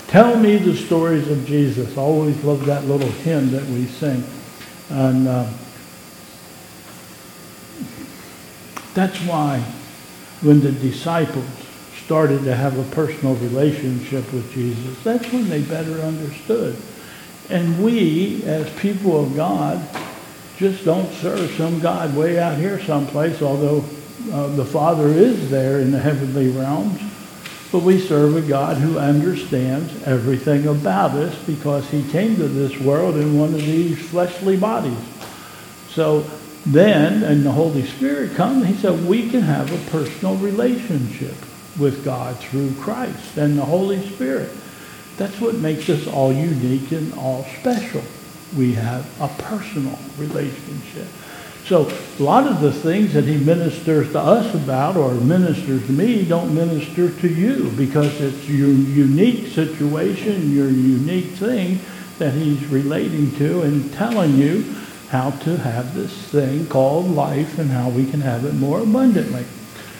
Message: "The Best Seat"